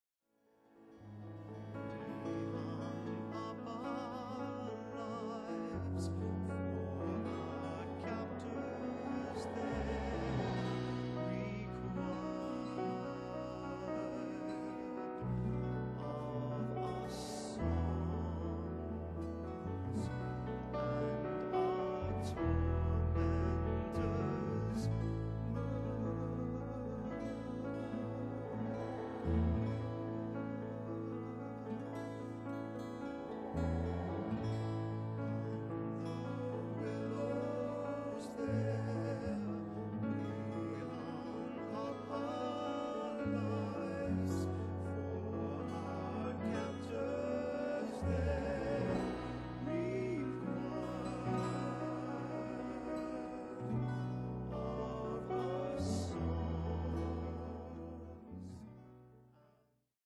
Category: Broadway, Film and Shows